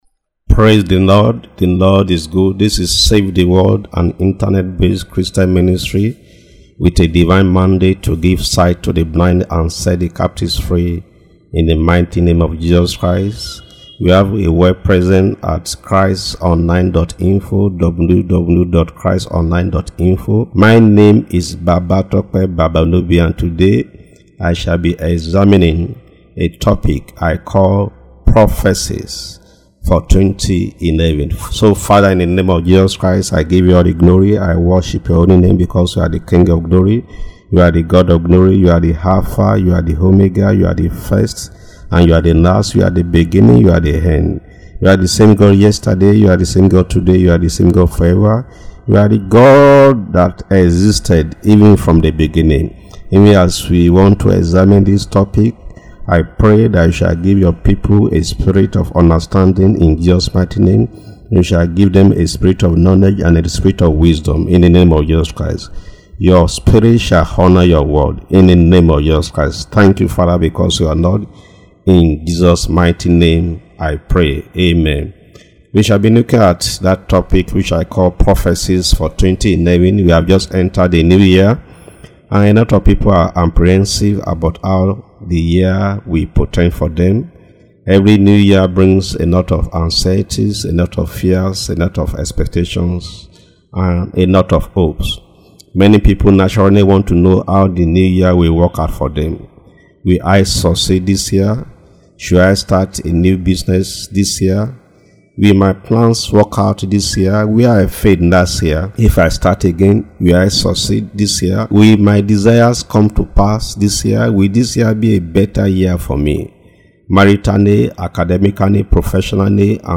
Audio sermon: Prophecies and predictions for New Year - Save the World Ministry